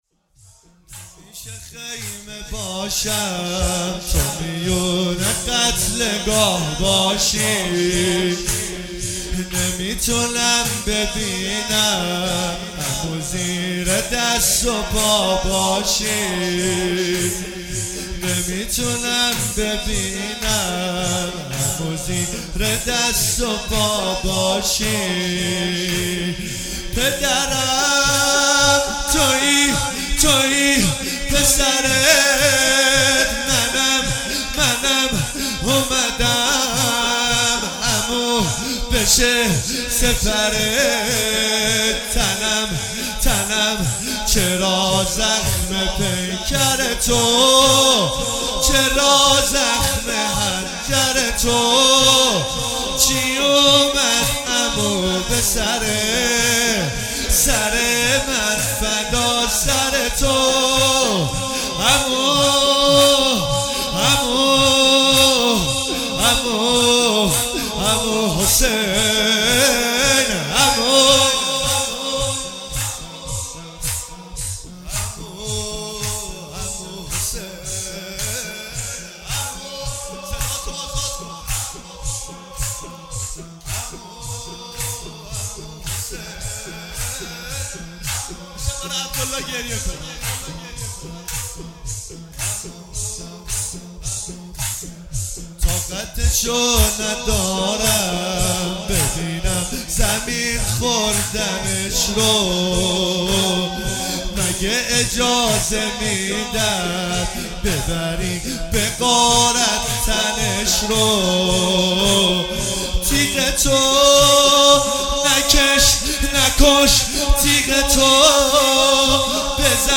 هیئت ام المصائب سلام الله علیها